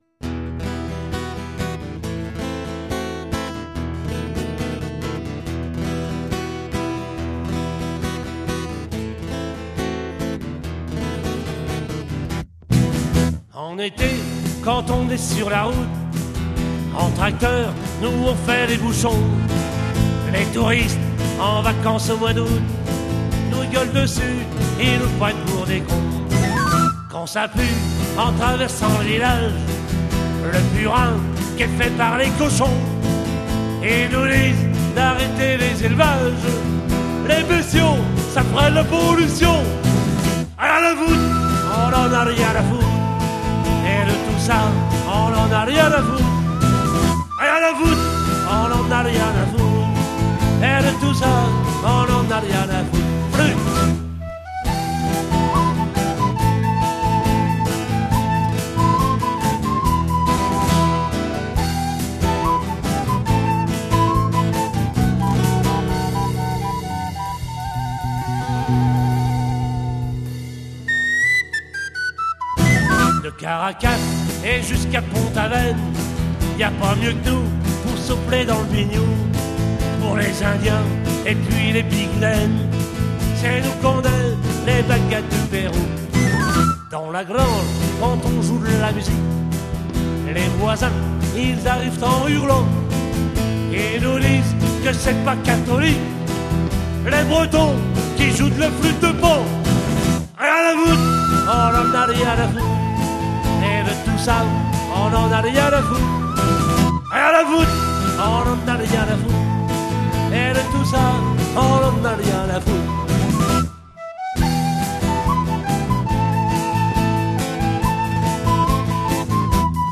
Maquette réalisée en Octobre 2005
aux studios du Micro-Bleu - RUCA (Côtes du Nord)
batterie
chant, guitare acoustique
guitare basse
whistles
accordéon diatonique